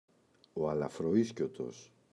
αλαφροΐσκιωτος [alafroꞋiscotos] – ΔΠΗ